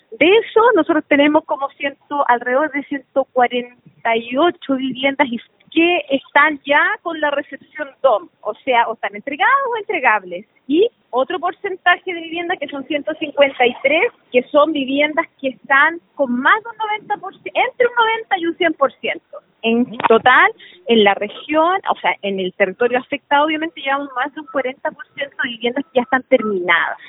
La seremi de vivienda en el Bío Bío, Claudia Toledo, aseguró que el proceso avanza, y esperan dejar lo más adelantado que se pueda antes que termine este Gobierno, de hecho, espera que pueda ser cerca del 90%.